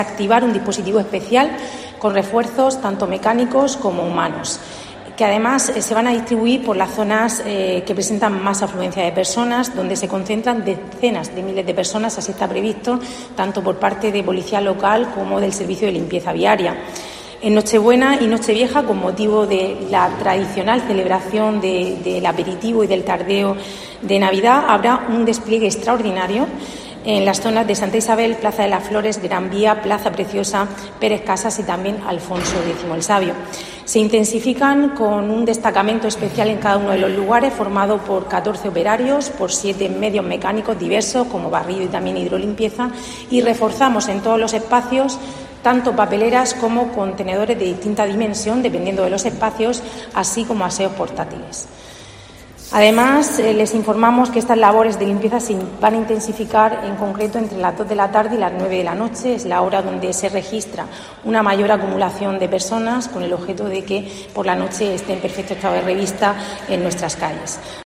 Rebeca Pérez, vicealcaldesa y concejala de Fomento y Patrimonio